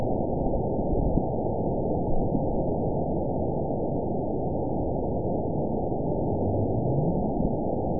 event 916733 date 01/18/23 time 22:26:45 GMT (2 years, 5 months ago) score 9.66 location TSS-AB10 detected by nrw target species NRW annotations +NRW Spectrogram: Frequency (kHz) vs. Time (s) audio not available .wav